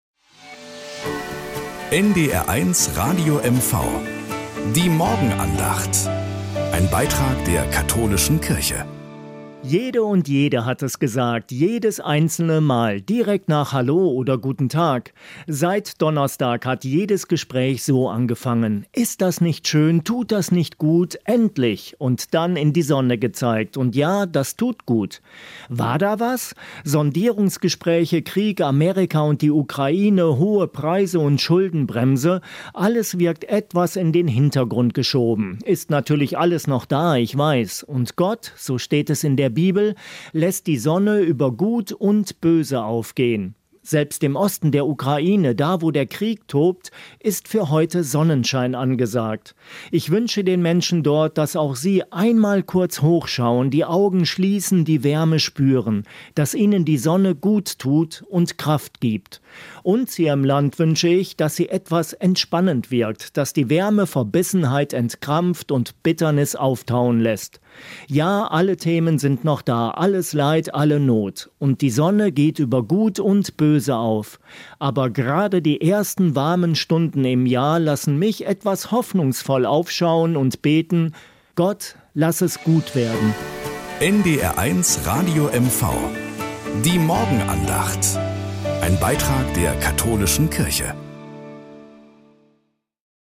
Nachrichten aus Mecklenburg-Vorpommern - 28.04.2025